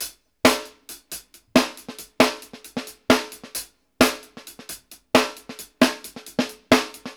HAT   SNAR-L.wav